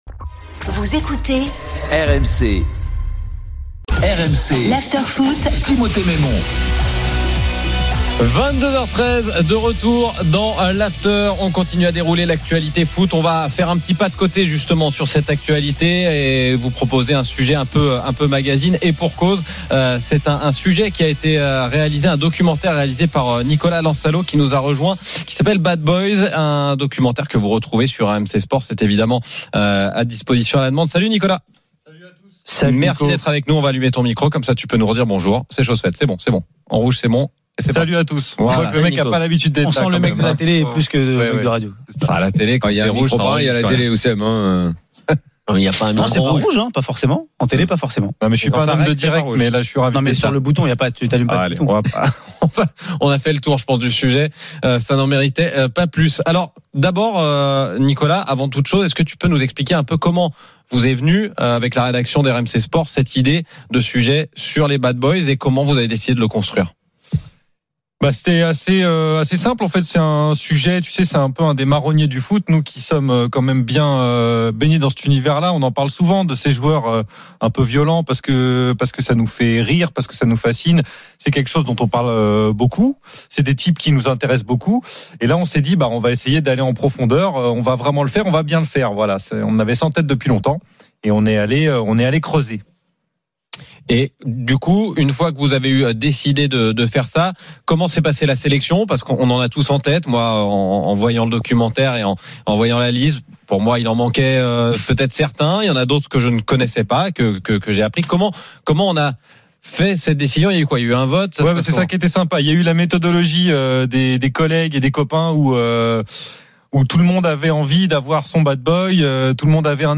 Chaque jour, écoutez le Best-of de l'Afterfoot, sur RMC la radio du Sport !
Les rencontres se prolongent tous les soirs avec Gilbert Brisbois et Nicolas Jamain avec les réactions des joueurs et entraîneurs, les conférences de presse d'après-match et les débats animés entre supporters, experts de l'After et auditeurs.